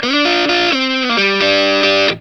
BLUESY3 G 90.wav